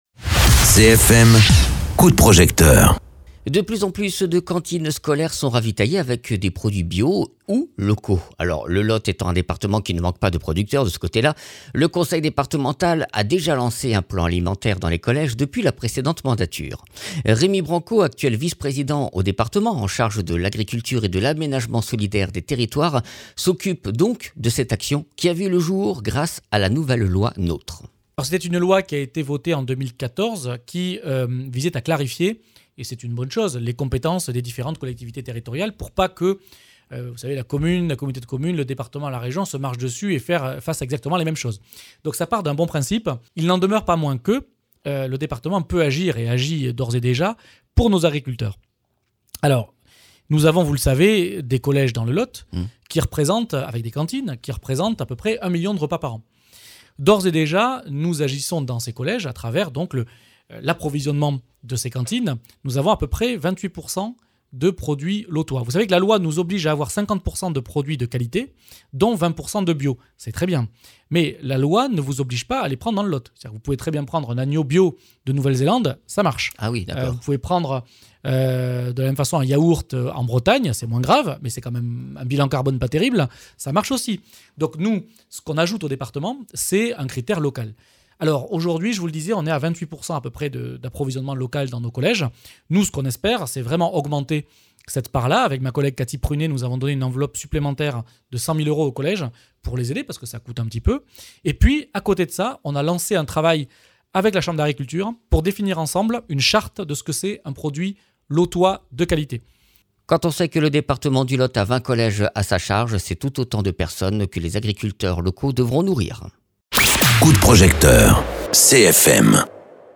Interviews
Invité(s) : Remi Branco, vice président au département du lot en charge de l’agriculture et de l’aménagement solidaire des territoires.